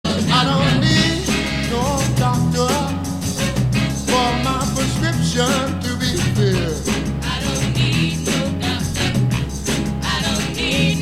джаз и блюз: